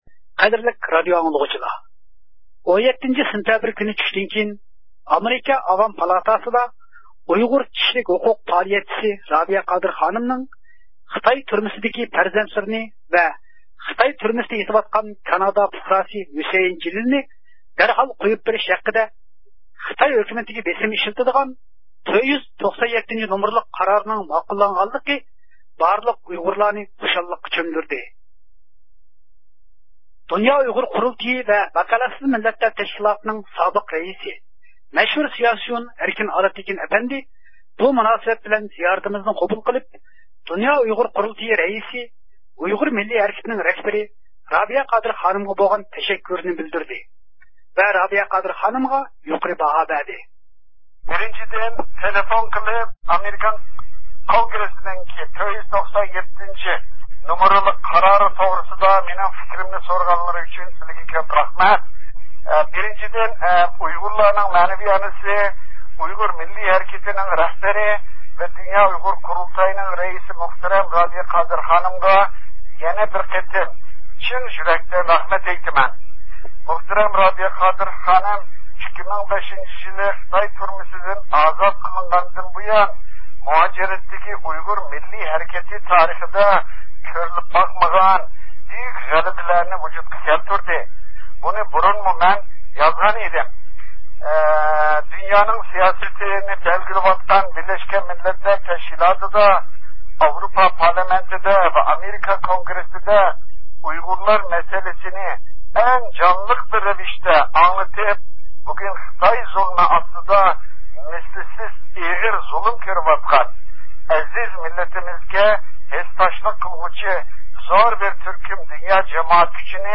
د ئۇ ق ۋە ۋەكالەتسىز مىللەتلەر تەشكىلاتىنىڭ سابىق رەئىسى، مەشھۇر سىياسىيون ئەركىن ئالىپتېكىن ئەپەندى بۇ مۇناسىۋەت بىلەن زىيارىتىمىزنى قوبۇل قىلىپ، د ئۇ ق رەئىسى، ئۇيغۇر مىللىي ھەرىكىتىنىڭ رەھبىرى رابىيە قادىر خانىمغا بولغان تەشەككۈرىنى بىلدۈردى ۋە رابىيە قادىر خانىمغا يۇقىرى باھا بەردى.